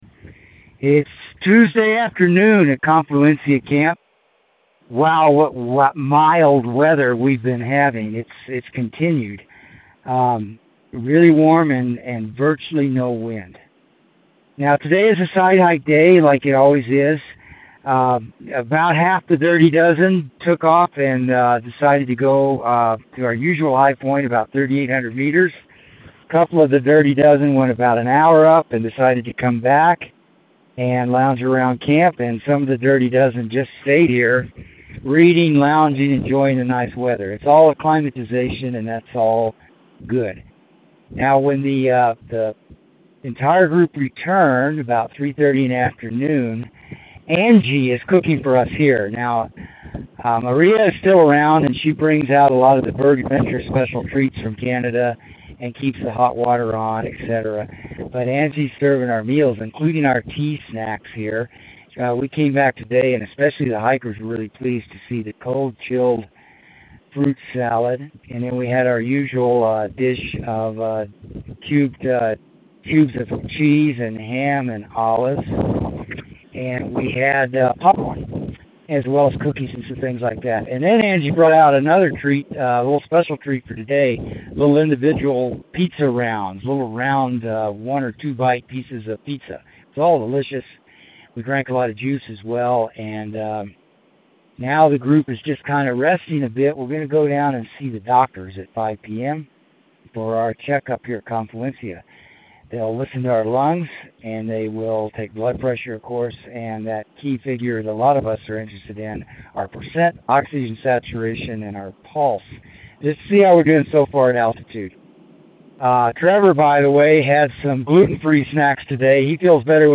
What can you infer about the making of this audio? It’s Tuesday afternoon at Confluencia Camp.